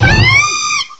cry_not_braixen.aif